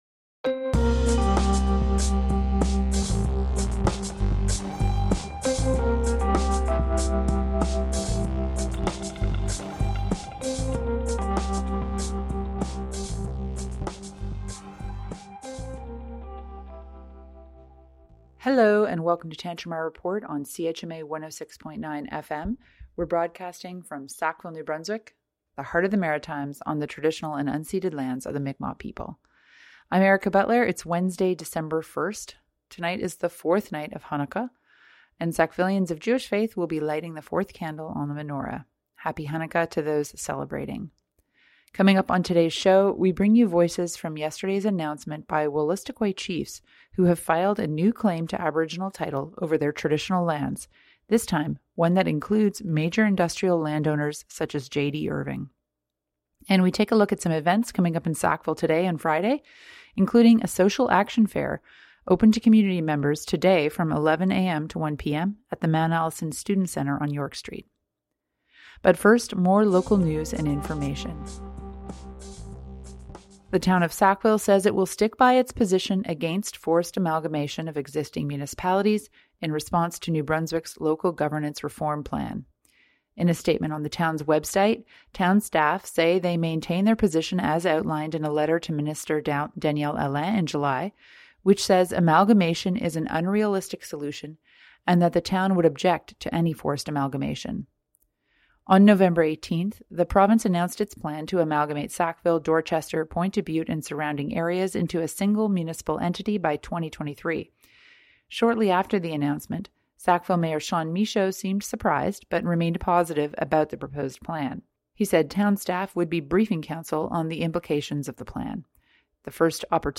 Listen to Tantramar Report to hear voices from the Chiefs’ announcement.